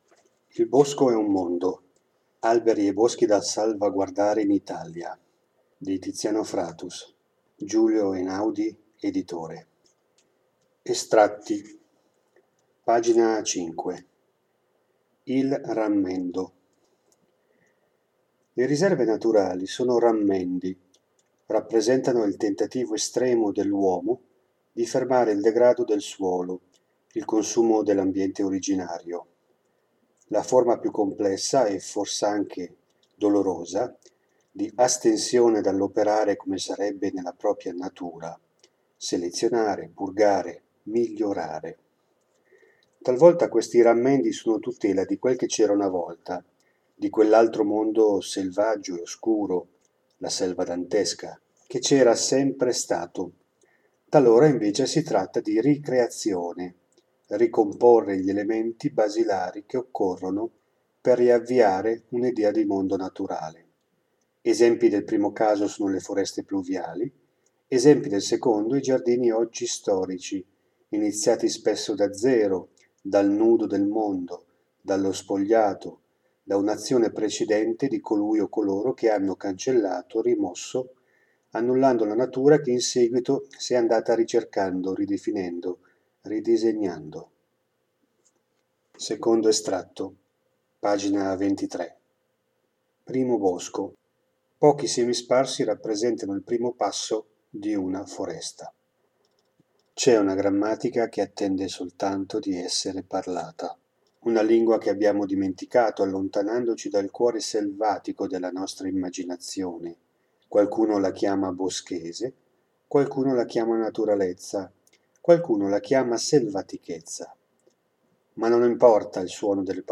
A seguire una selezione di brani e passi letti per voi.
Dettaglio: letture dall’introduzione, Il rammendo, quindi passi estrapolati dal Primo bosco: Pochi semi sparsi rappresentano il primo passo di una foresta, Il boschese e l’immersione nell’Italia dei Patriarchi.